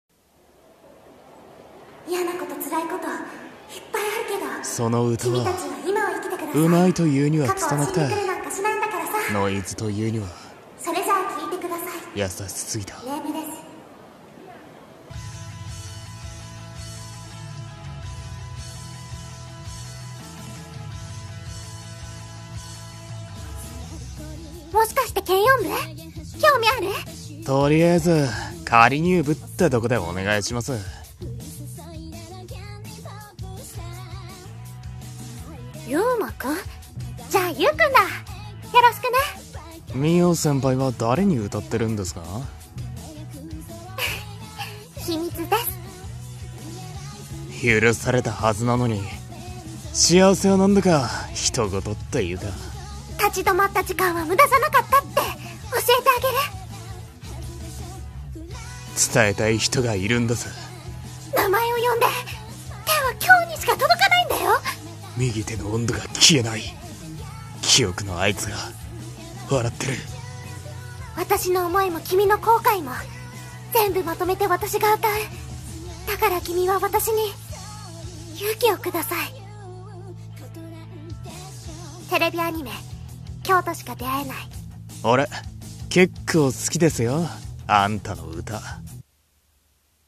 【アニメCM風声劇】今日としか出会えない